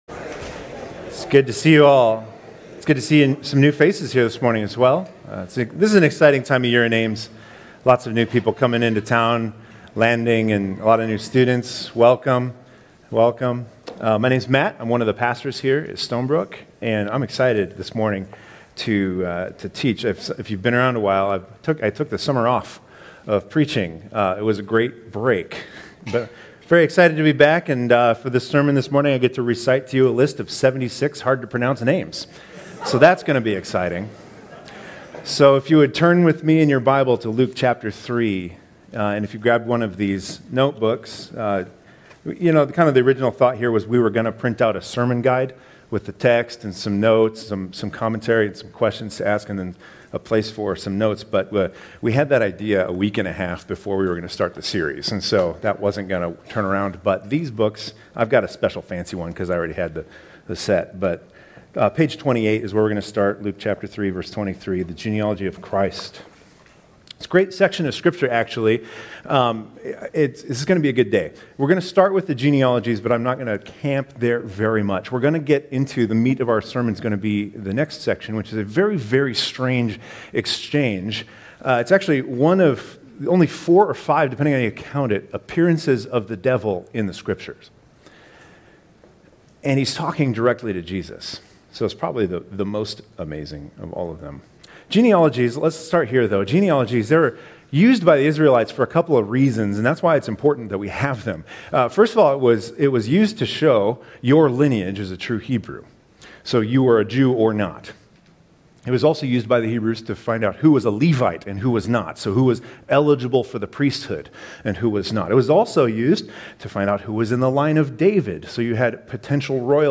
The Gospel According to Luke Luke I’m very excited for today’s sermon.